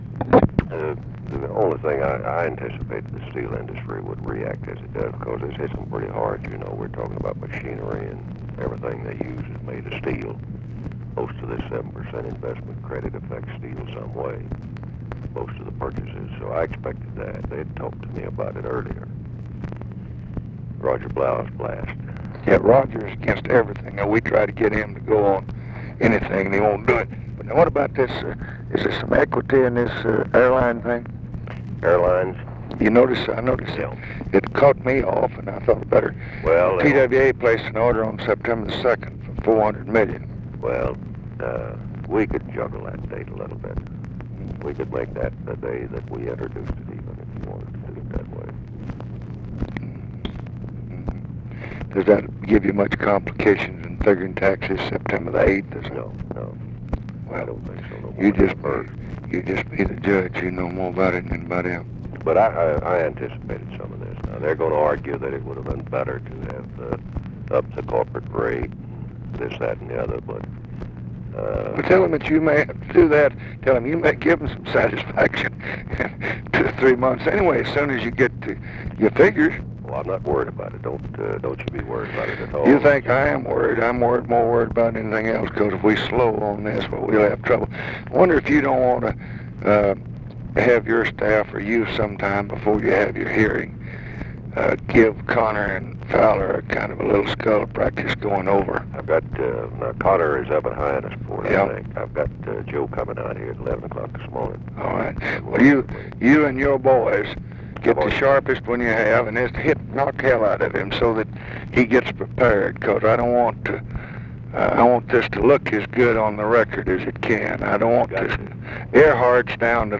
Conversation with WILBUR MILLS, September 9, 1966
Secret White House Tapes